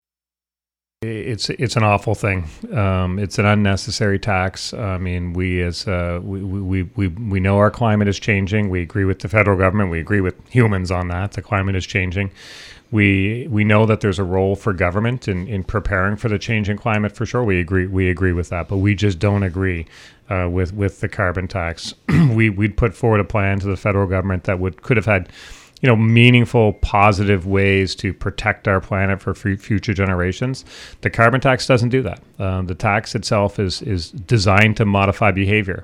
Tim Houston, premier ministre de la Nouvelle-Écosse et Allan MacMaster, vice-premier ministre et député pour le comté d'Inverness étaient de passage dans les studios de Radio CKJM à Chéticamp.